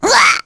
Nia-Vox_Damage_03.wav